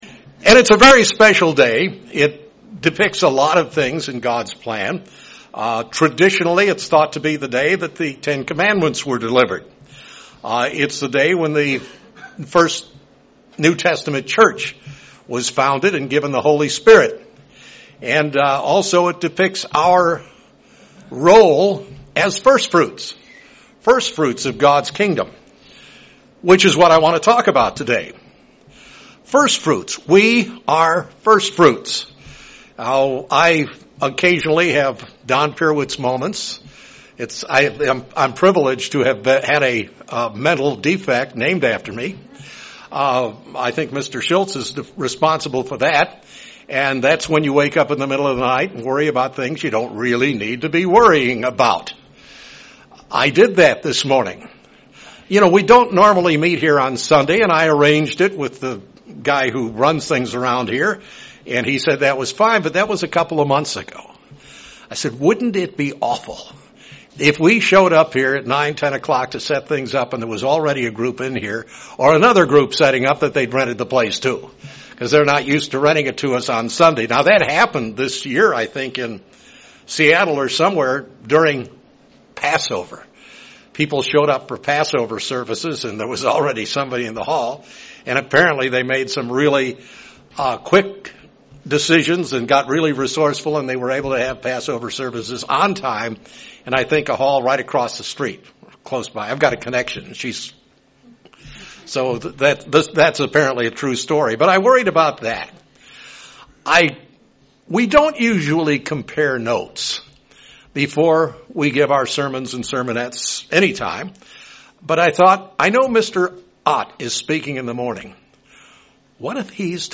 Given in Springfield, MO
UCG Sermon Studying the bible?